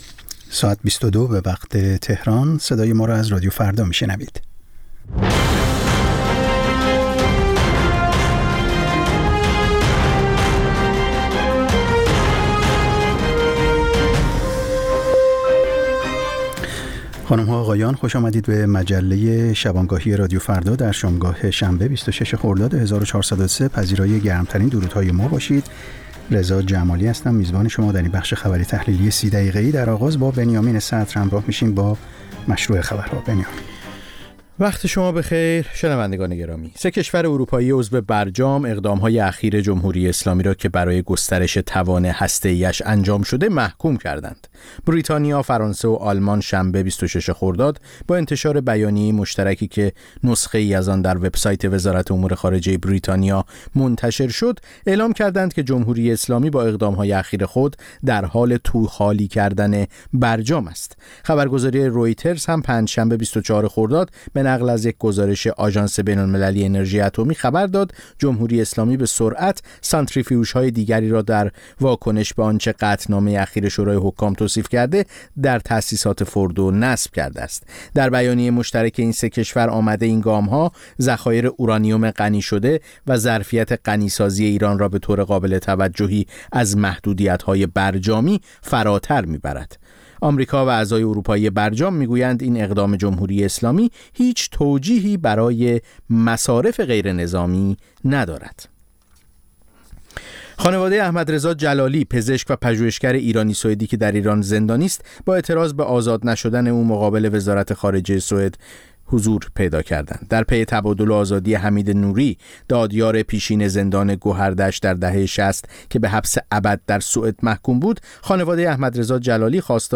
نیم ساعت با تازه‌ترین خبرها، گزارش‌های دست اول در باره آخرین تحولات جهان و ایران از گزارشگران رادیو فردا در چهارگوشه جهان، گفت‌وگوهای اختصاصی با چهره‌های خبرساز و کارشناسان